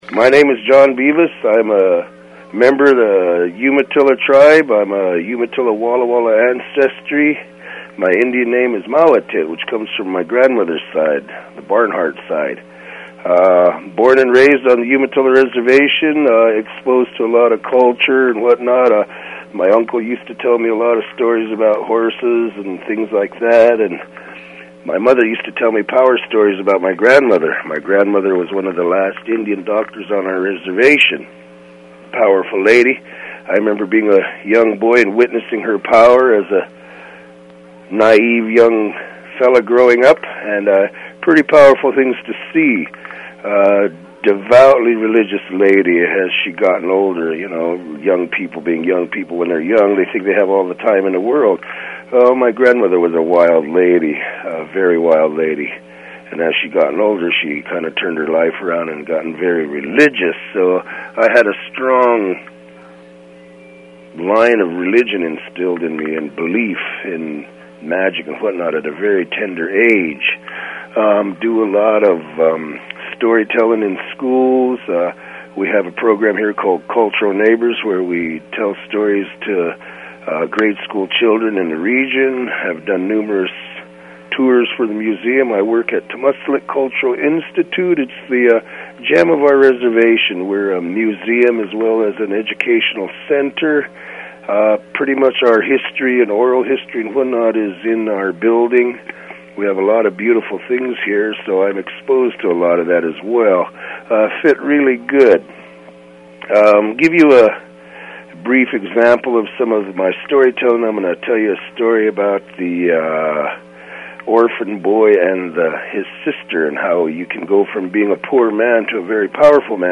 Oregon, Storytelling, Turtle Island Storytellers Network